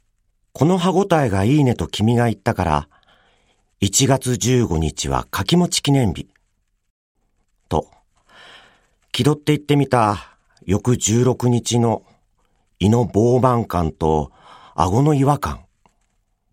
ボイスサンプル
朗読